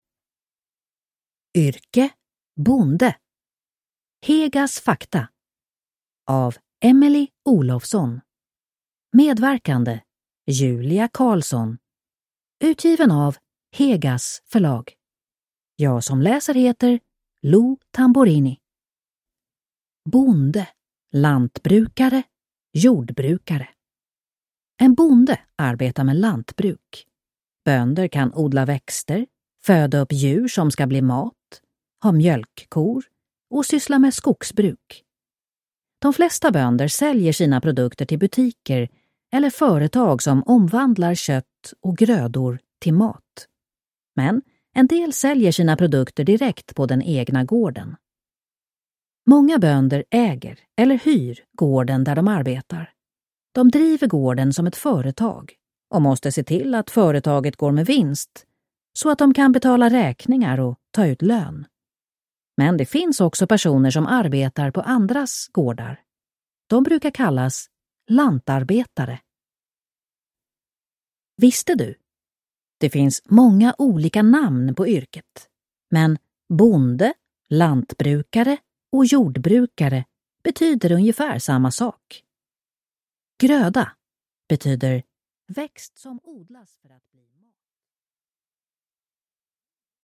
Bonde (ljudbok) av Emelie Olofsson